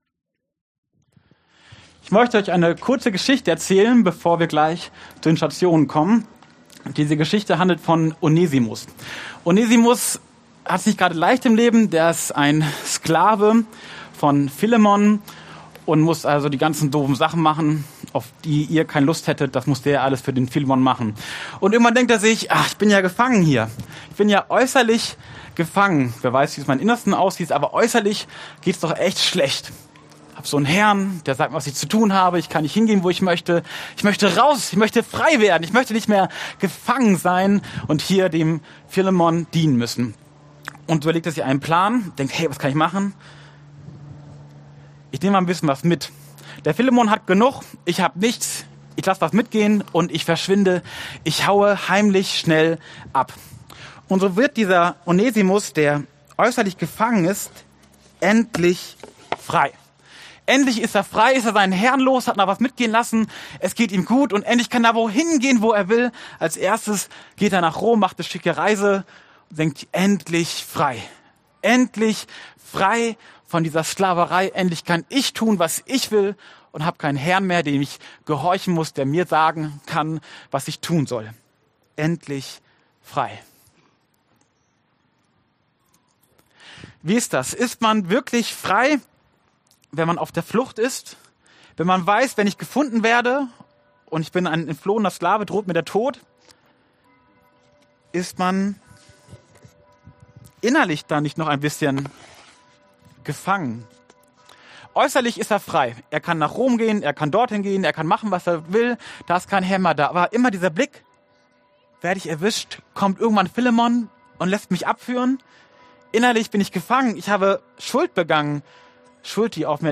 Ranger-Gottesdienst: „Frei sein“